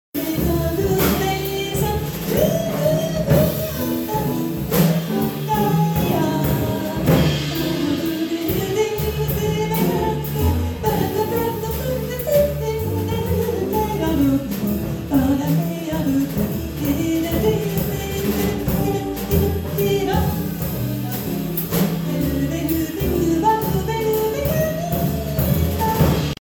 The renowned jazz vocalist brought some of her most talented musician friends to the theater for an intimate dinner and concert with close to 100 people.
guitarist
vibist
percussionist
bassist